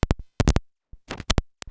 clicks